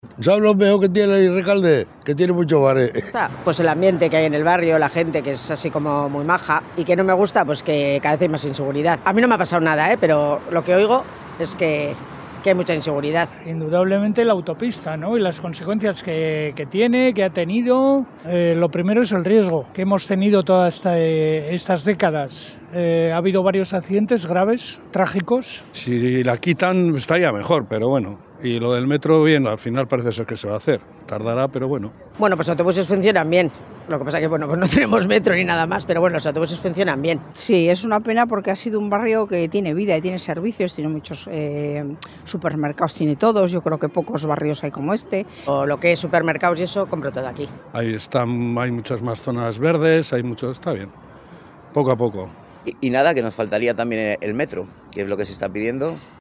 Rekalde ha sido el protagonista de una nueva edición de Bilbao al habla, espacio que recoge las voces vecinales para conocer las inquietudes y fortalezas de los barrios bilbaínos.